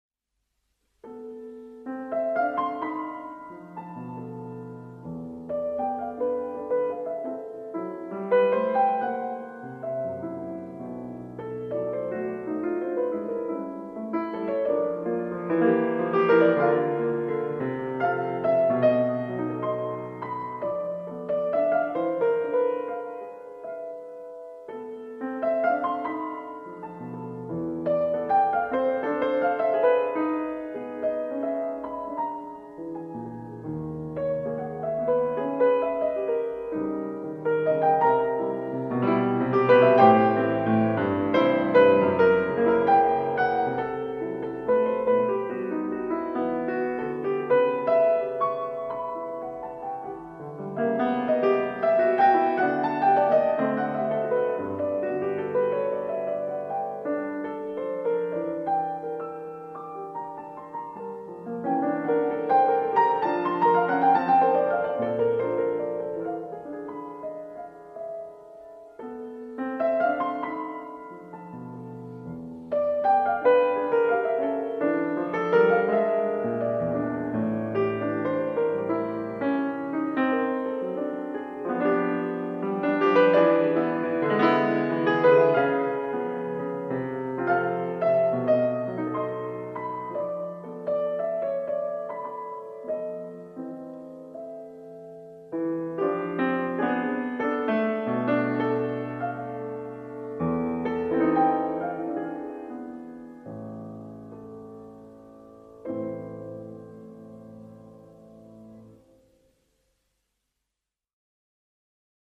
Живая запись с концерта:
Прелюдии А.Скрябина:  Op.11, №23 f-dur
Op.16, №4 es-moll
Op.22, №2, cis-moll
Op.11, №2, a-moll
Op.11, №4 e-moll